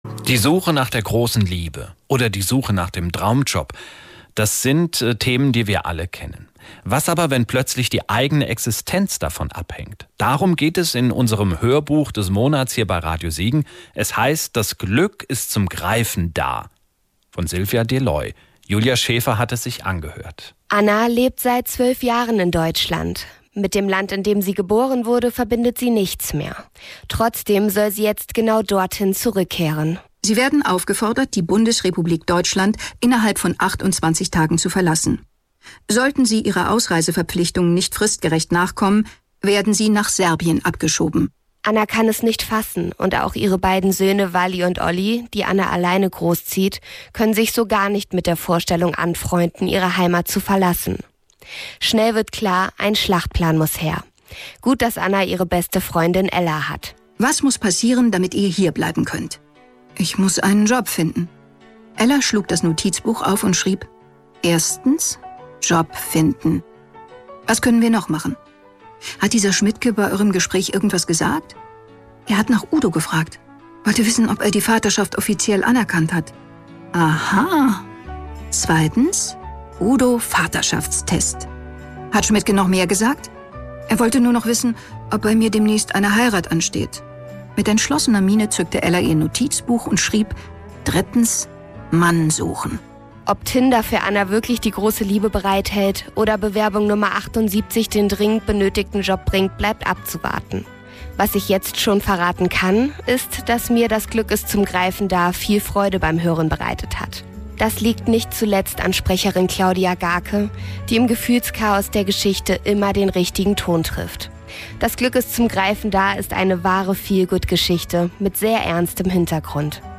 Alle Hörbücher des Monats findet Ihr unter: Hörbuch des Monats